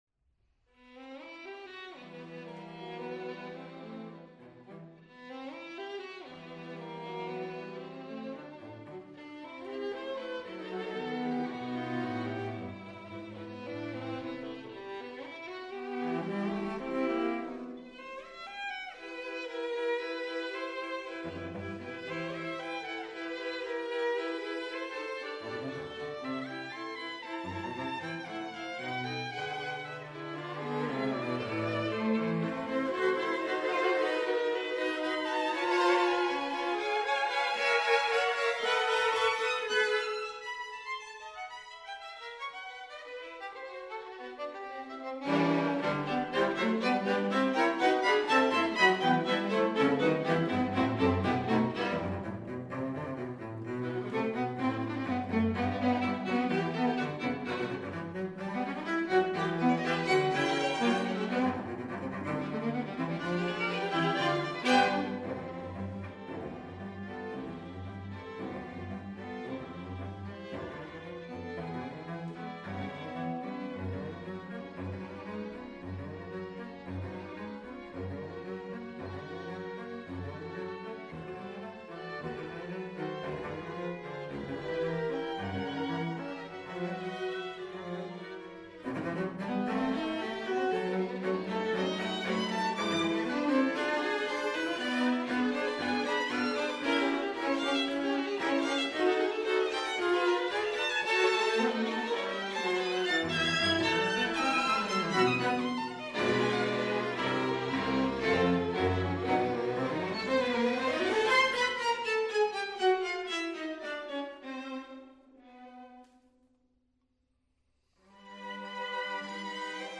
Quartetto per archi